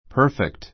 perfect 中 A2 pə́ː r fikt パ ～ふェ クト 形容詞 完全な , 申し分ない; 全くの a perfect crime a perfect crime 完全犯罪 That's perfect.